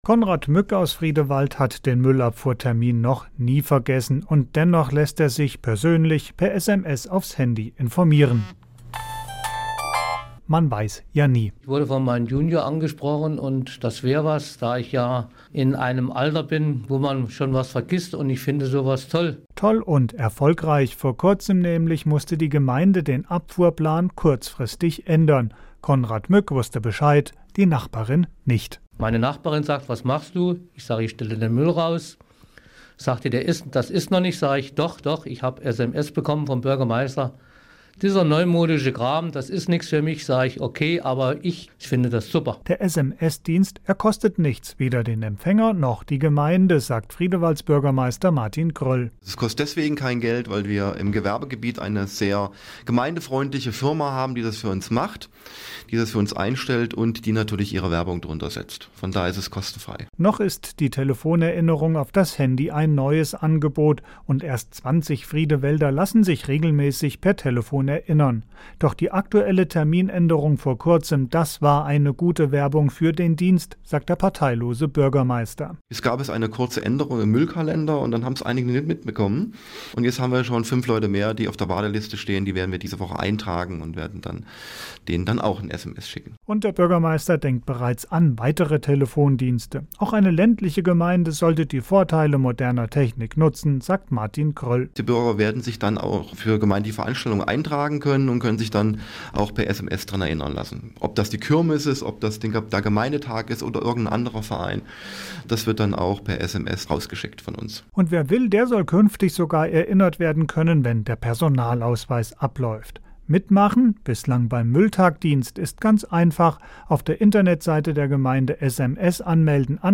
Beitrag des Hessischen Rundfunks zur M?ll-SMS MP3-Datei (ca. 2,05 MB)